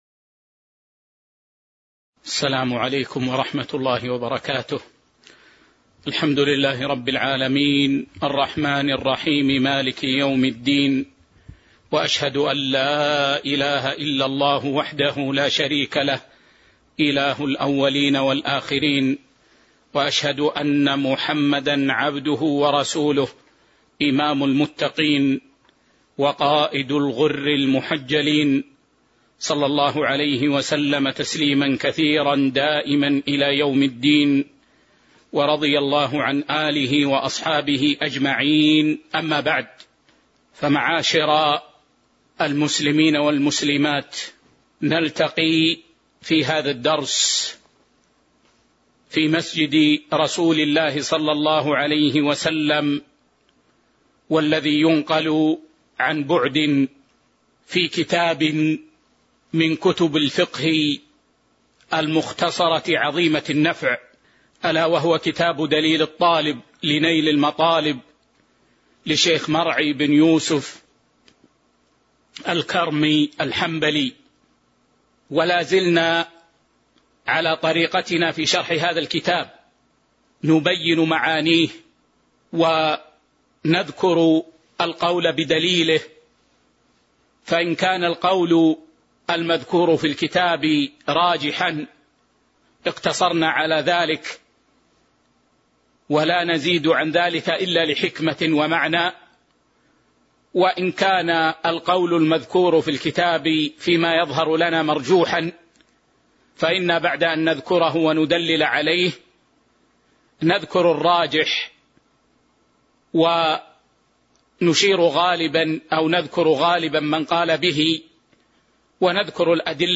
تاريخ النشر ٦ ربيع الثاني ١٤٤٢ هـ المكان: المسجد النبوي الشيخ